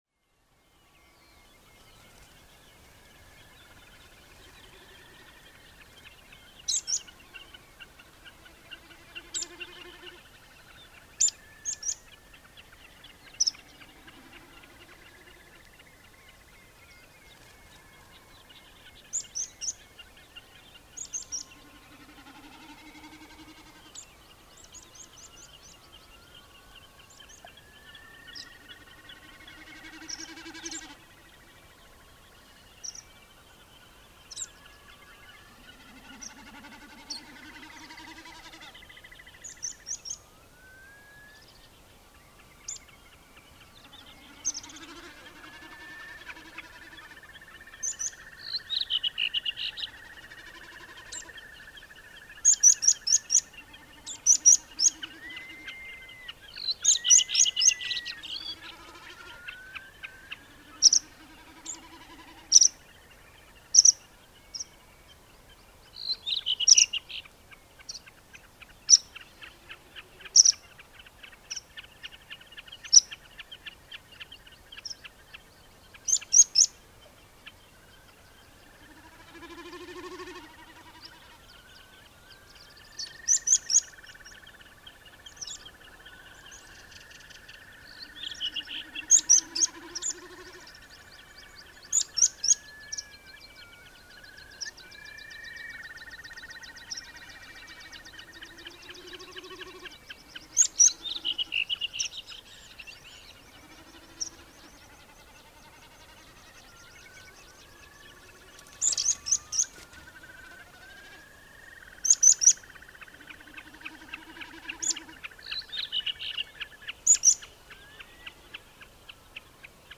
Að sjálfsögðu hafði ég með mér Sony TC-D5 upptökutæki og ME20 hljóðnema. Þegar klukkan var farin að nálgast 4 að morgni rölti ég til suðurs frá tjaldsvæðinu austur af Laugarvatni og hóf upptöku líklega um 500 metra frá veginum.
Hér er á ferðinni mjög lágstemmd upptaka sem alltaf hefur verið í miklu uppáhaldi hjá mér.
Hér eru bæði spóar og hrossagaukar í sínu besta skapi en á móti virðist sem ég hafi verið mjög nærri hreiðri músarindils. Hvell viðvörunartíst frá honum skera svolítið í eyrun. Ekki síst þar sem upptökutæki þessa tíma ráða ekki vel við þessa háu tíðni. Músarindillinn skoppar hér á milli greina í lágu kjarri sem þá mátti finna á einstaka stað á þessu svæði. Heyra má í öðrum fuglum eins og þröstum og hröfnum. Tekið var upp á TDK MA90 kassettu.